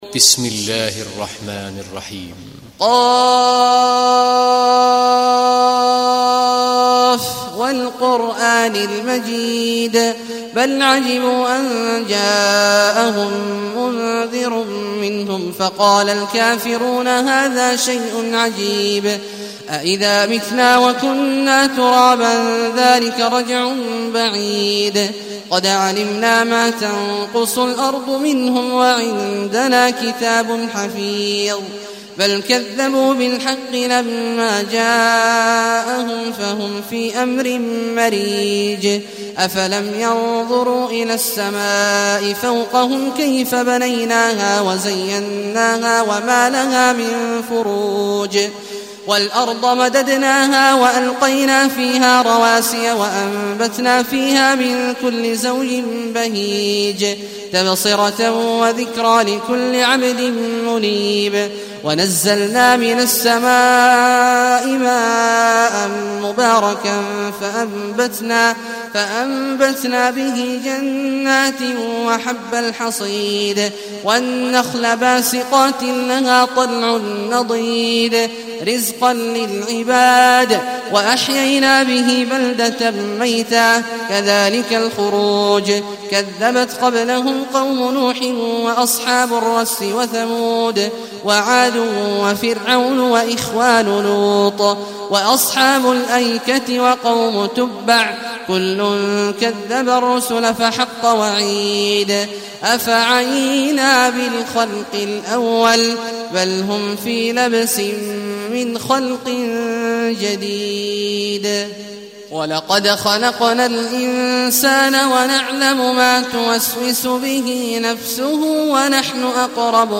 Kaf Suresi İndir mp3 Abdullah Awad Al Juhani Riwayat Hafs an Asim, Kurani indirin ve mp3 tam doğrudan bağlantılar dinle